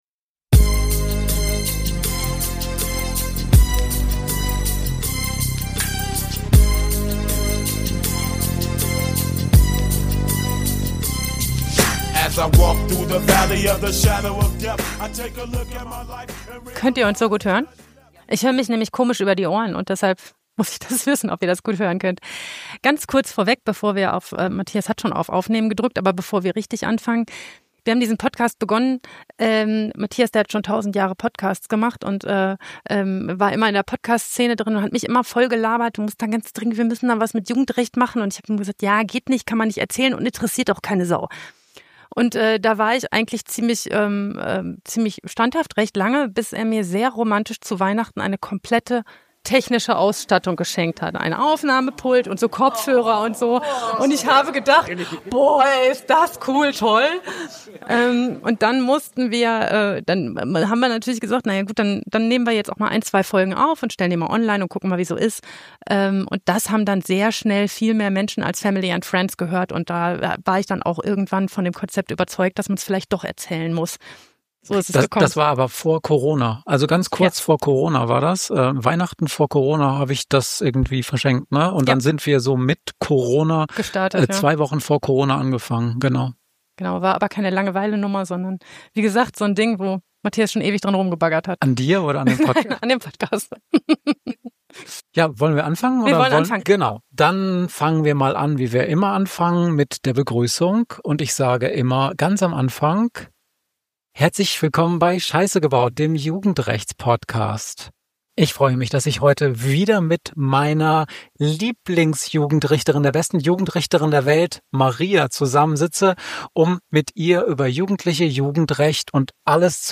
Wir waren zu einem Fachtag des LKA in Niedersachsen zum Thema Jugendsachen eingeladen und haben einen Live-Podcast aufgenommen. Im heutigen Fall geht es um einen Zeugen, der große Angst vor dem Angeklagten hat und dessen Situation bis zum Prozessbeginn keinem der anwesenden Profis klar gewesen ist.